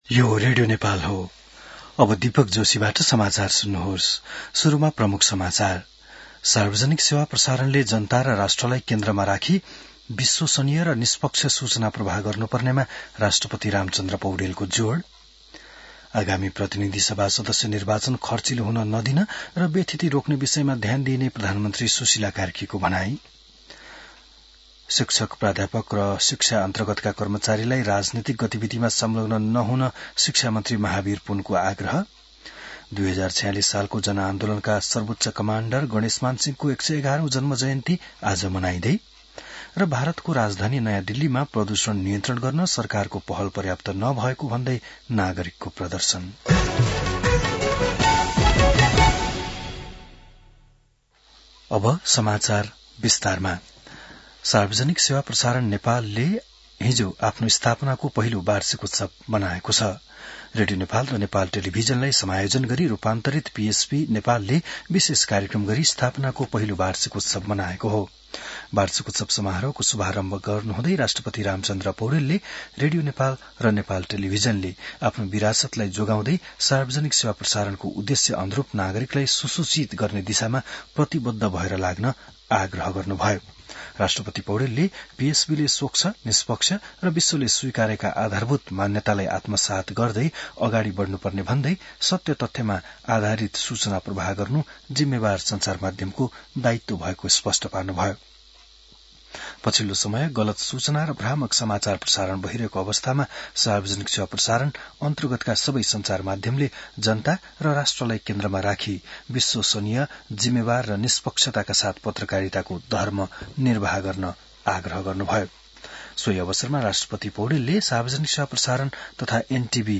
बिहान ९ बजेको नेपाली समाचार : २४ कार्तिक , २०८२